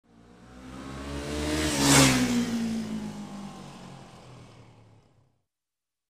Мотоцикл проезжает мимо быстро
• Категория: Мотоциклы и мопеды
• Качество: Высокое
На этой странице вы можете прослушать звук мотоцикл проезжает мимо быстро.